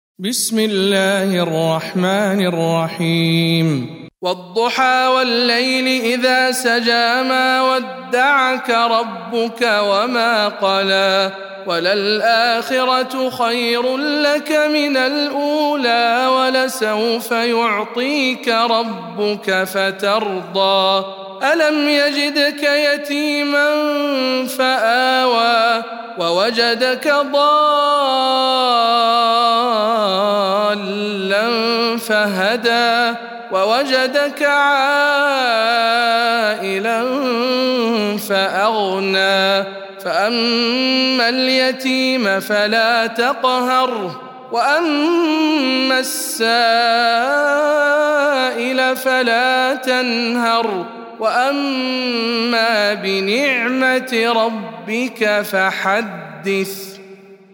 سورة الضحى - رواية ابن جماز عن أبي جعفر